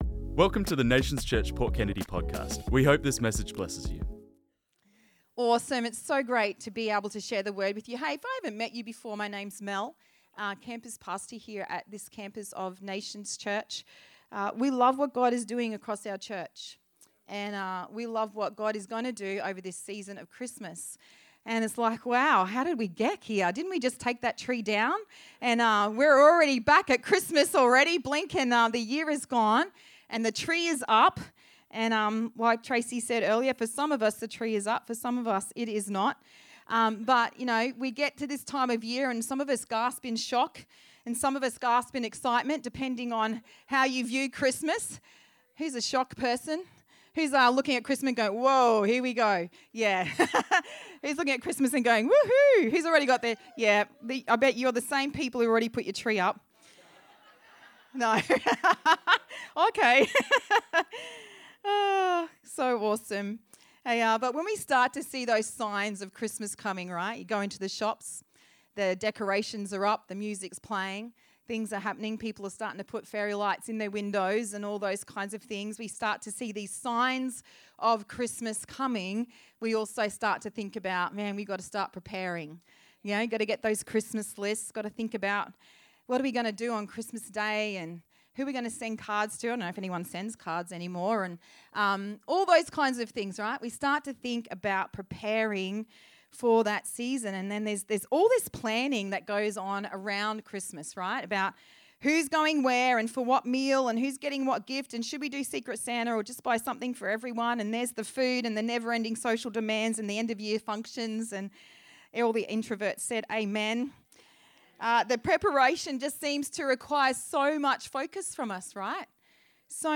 This message was preached on Sunday 3rd Nov 2024.